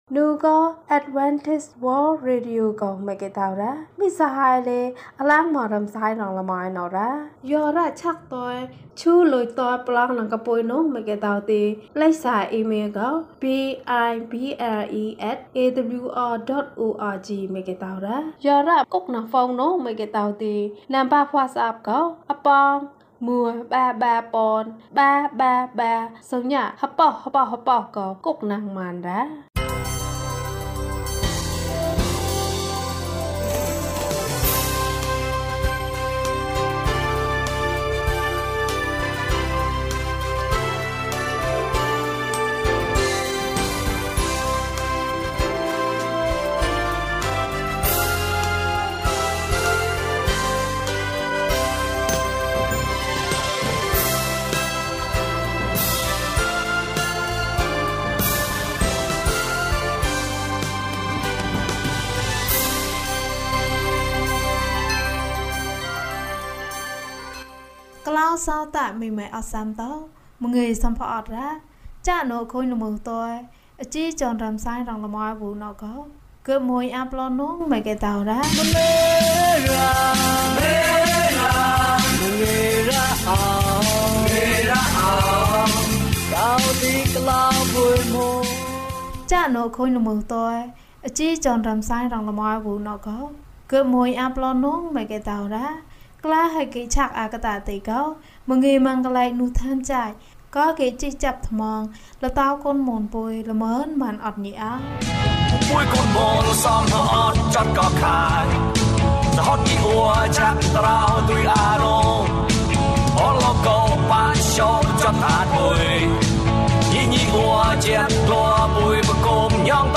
ကြောင်နဲ့ ကလေးမလေး ဇာတ်လမ်း။ ကျန်းမာခြင်းအကြောင်းအရာ။ ဓမ္မသီချင်း။ တရားဒေသနာ။